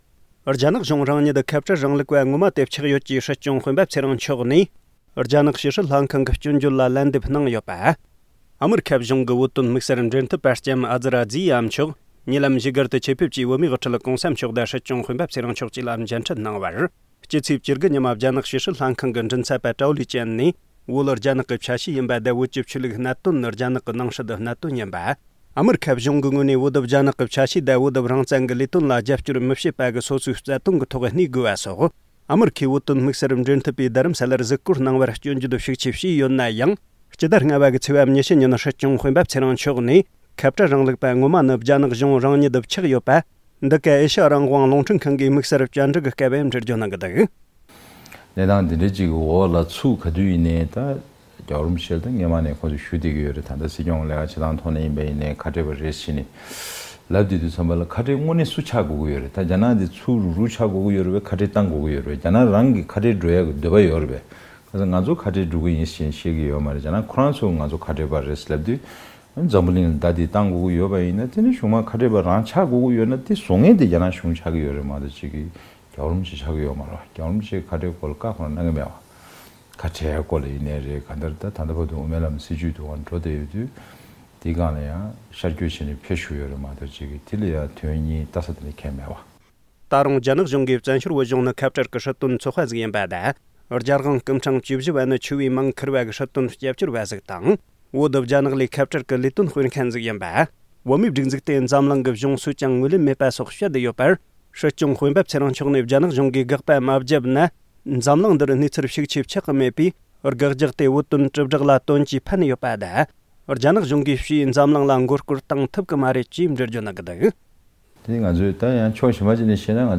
རྒྱ་ནག་གཞུང་རང་ཉིད་ཁ་བྲལ་རིང་ལུགས་པ་ངོ་མ་དེ་ཆགས་ཡོད་ཅེས་སྲིད་སྐྱོང་སྤེན་པ་ཚེ་རིང་མཆོག་ནས། རྒྱ་ནག་ཕྱི་སྲིད་ལྷན་ཁང་གི་སྐྱོན་བརྗོད་ལ་ལན་འདེབས་གནང་ཡོད་པ། སྲིད་སྐྱོང་སྤེན་པ་ཚེ་རིང་ལགས་ནས། ཁ་བྲལ་རིང་ལུགས་པ་ངོ་མ་དེ་ནི་རྒྱ་ནག་གཞུང་རང་ཉིད་ཆགས་ཡོད་པ།འདི་ག་ཨེ་ཤེ་ཡ་རང་དབང་རླུང་འཕྲིན་ཁང་གིས་དམིགས་བསལ་བཅར་འདྲིའི་སྐབས་འགྲེལ་བརྗོད་གནང་གི་འདུག
སྒྲ་ལྡན་གསར་འགྱུར།